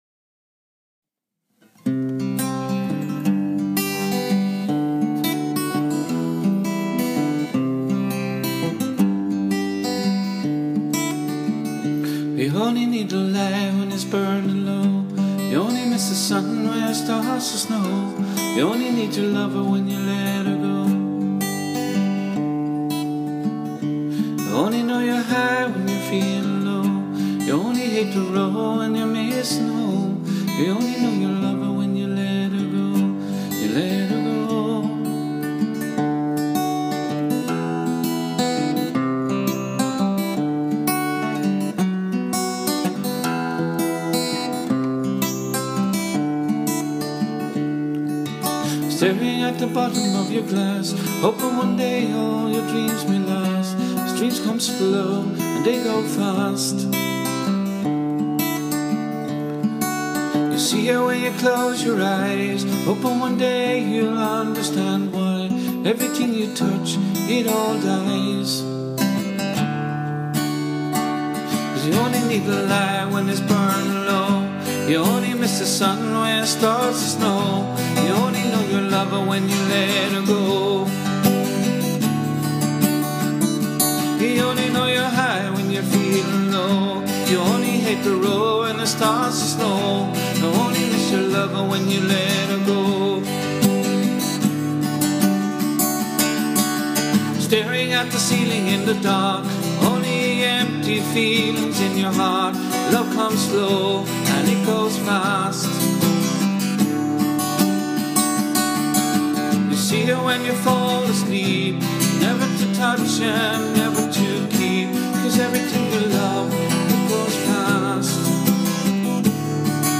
completely with the help of his voice, guitar and a Bodhran.